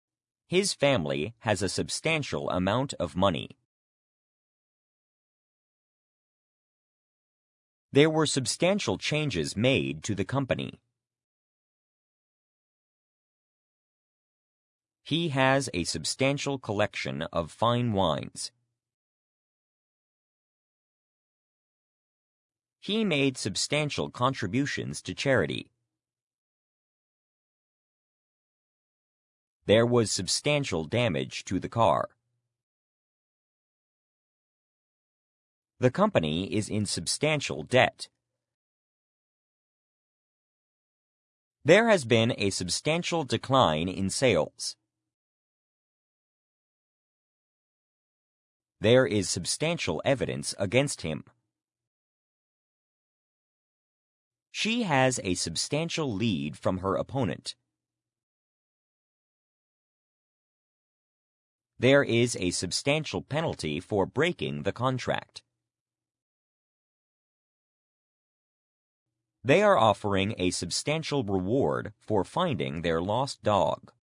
substantial-pause.mp3